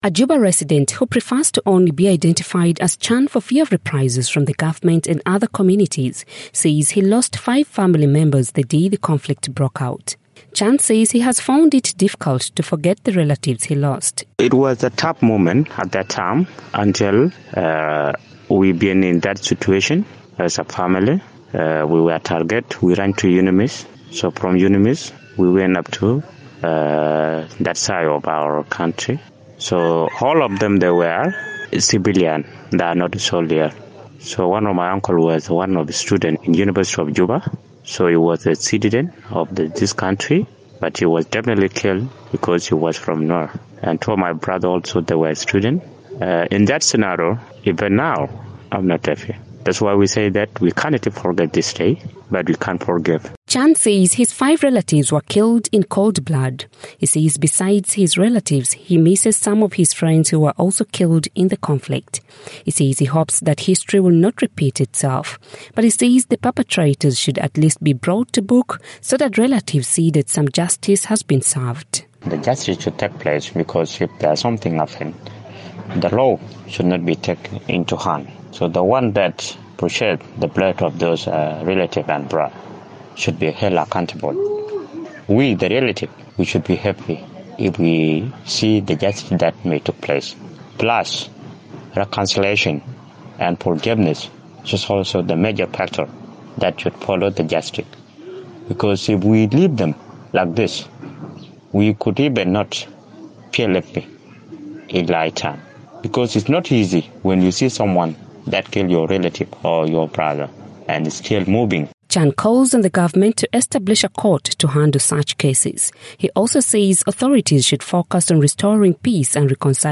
South Sudanese are today marking eight years since conflict broke out in the capital Juba on December 15th, 2013 between forces loyal to President Salva Kiir and those loyal to his deputy, then opposition leader Riek Machar. For South Sudan in Focus